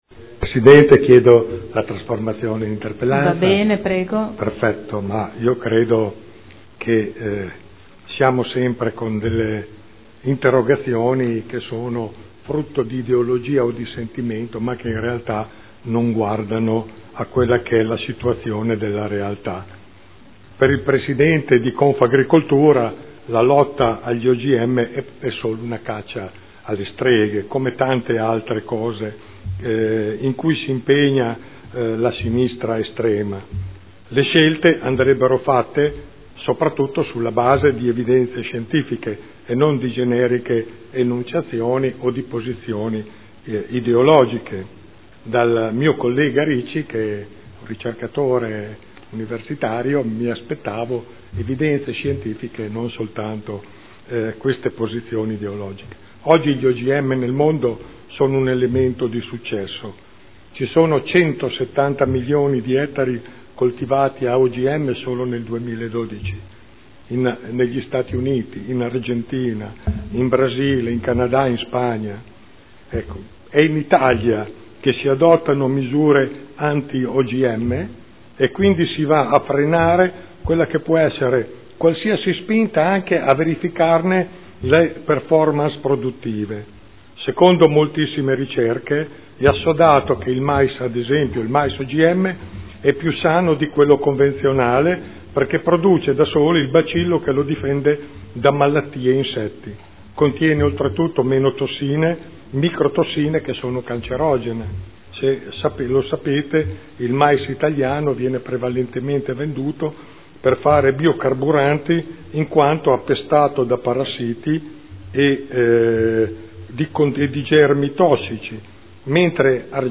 Seduta del 14/11/2013 Interrogazione del consigliere Ricci (SEL) avente per oggetto: “Modena OGM free” chiede la trasformazione in interpellanza.